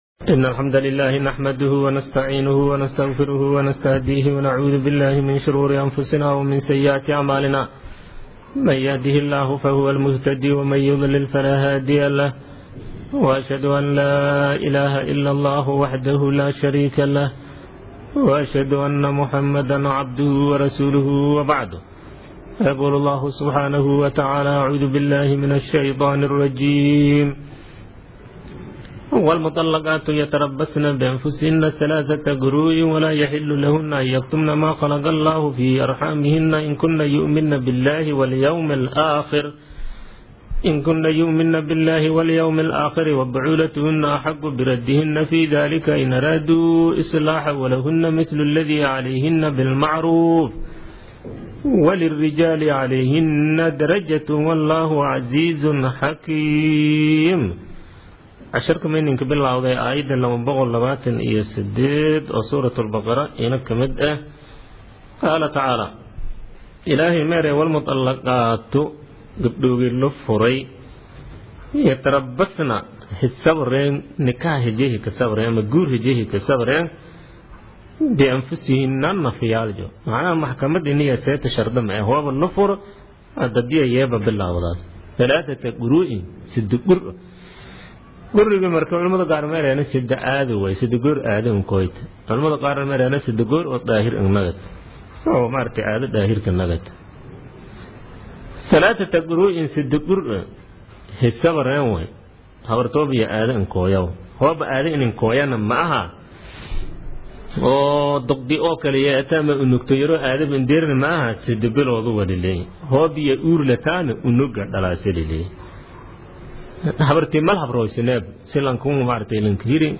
Casharka Tafsiirka Maay 29aad